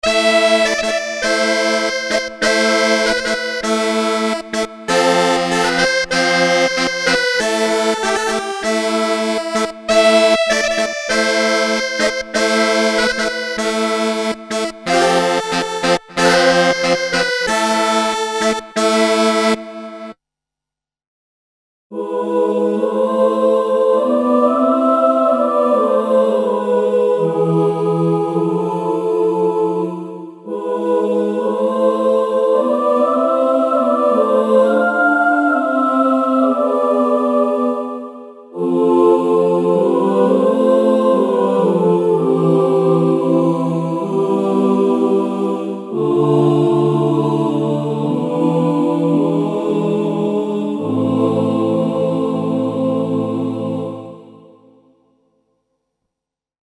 Collection of classical wind instruments (wood winds, flutes, trumpets, saxophones, brass sections) and voices.
Info: All original K:Works sound programs use internal Kurzweil K2500 ROM samples exclusively, there are no external samples used.